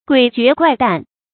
诡谲怪诞 guǐ jué guài dàn 成语解释 怪诞：奇异古怪。